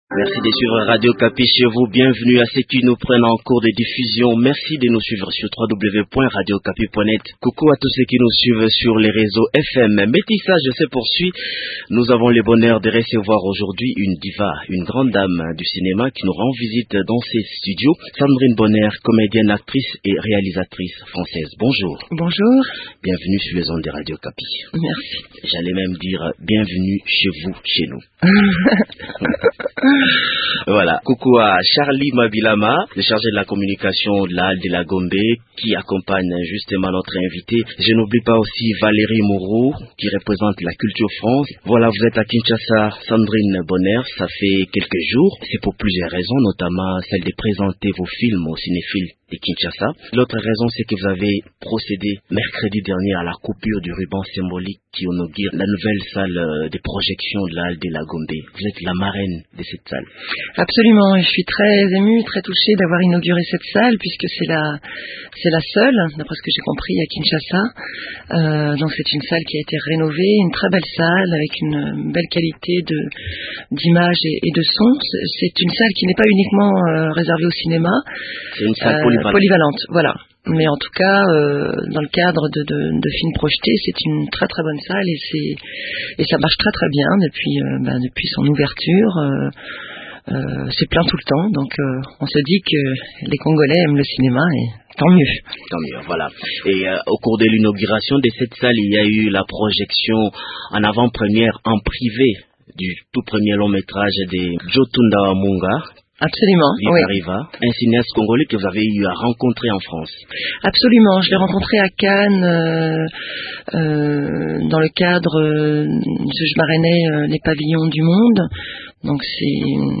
La Française est l’invitée de l’émission «Métissage».